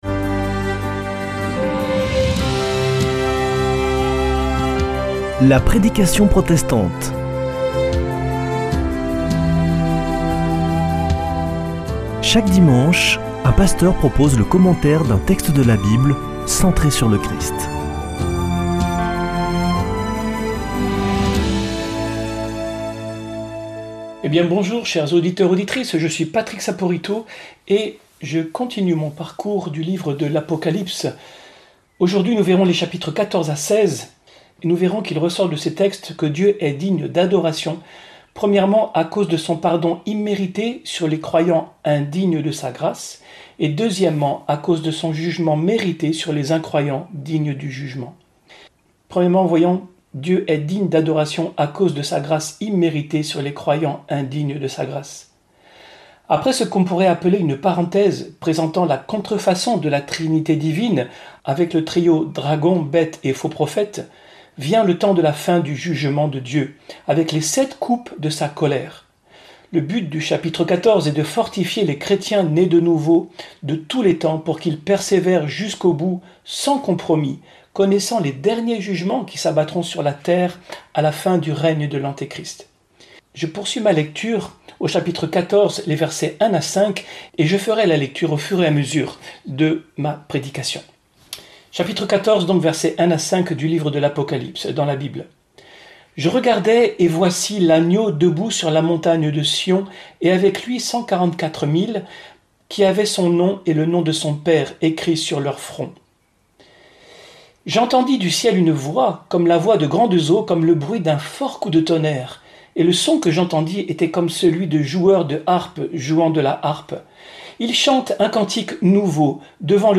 [ Rediffusion ] Dieu est digne d'adoration 1) pour son pardon immérité sur les croyants indignes de sa grâce 2) pour son jugement mérité sur ceux (...)
Accueil \ Emissions \ Foi \ Formation \ La prédication protestante \ LE LIVRE DE L’APOCALYPSE - CHAPITRES 14 à 16 - Apocalypse 14-16 La coupe est (...)
Une émission présentée par Des protestants de la région